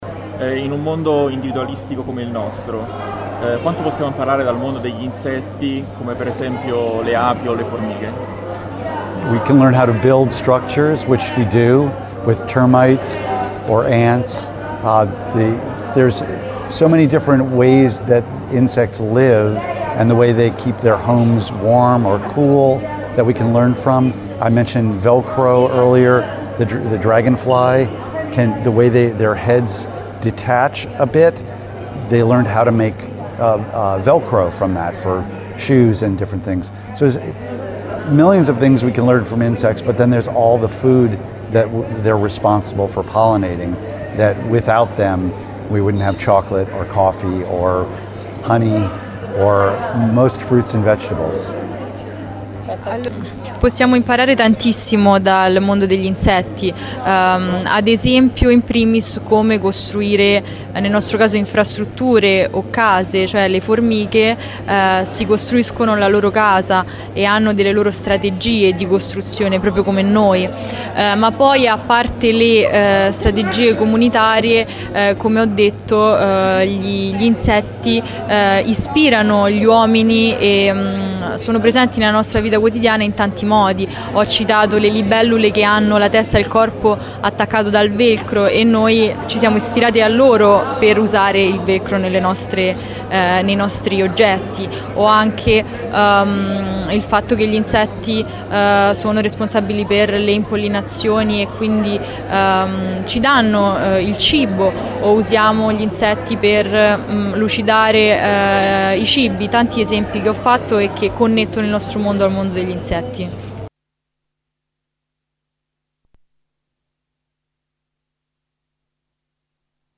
LATINA – Mattinata speciale al Teatro D’Annunzio dove questa mattina Peter Kuper ha presentato in un incontro dedicato alle scuole, la graphic novel “Insectopolis”, il suo nuovo lavoro edito da Tunuè.